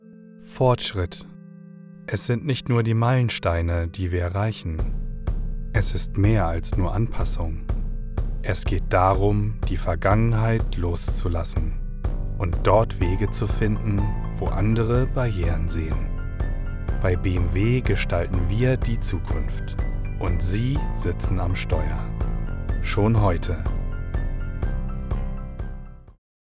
Imagefilm